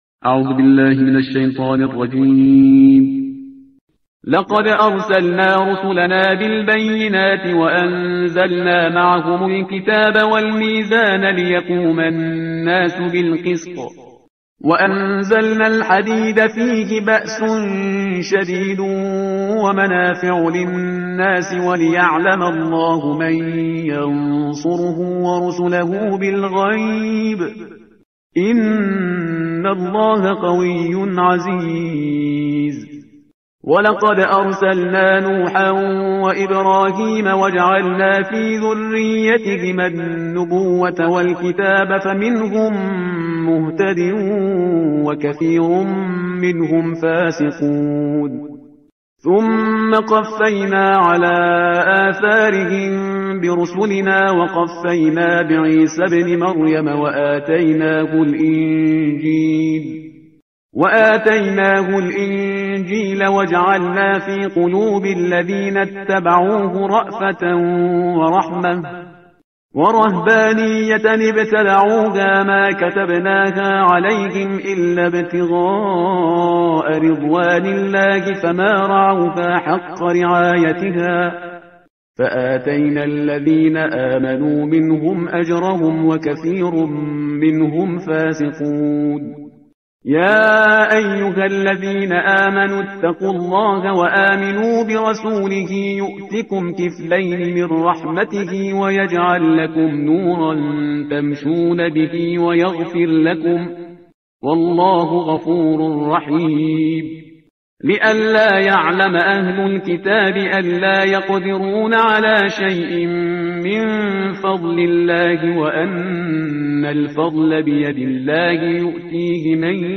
ترتیل صفحه 541 قرآن با صدای شهریار پرهیزگار